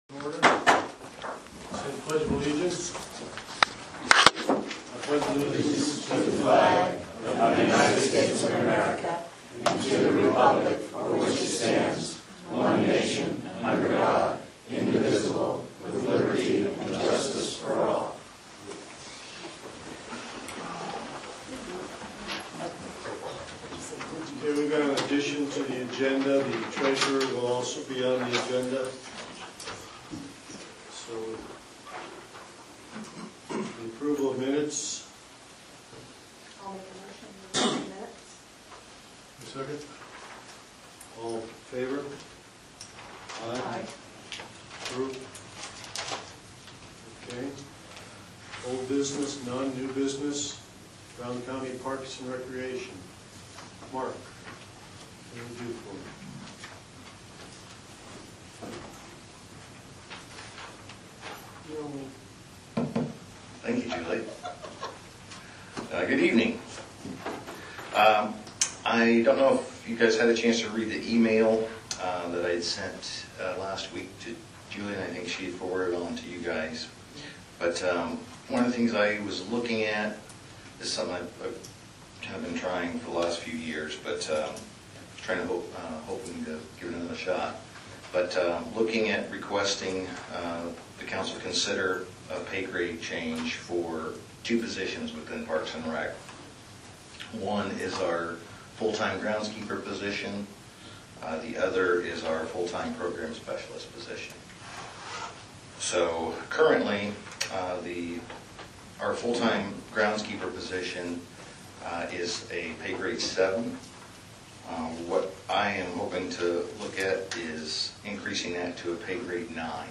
County Council Meeting Notes July 19, 2023, 6-8 pm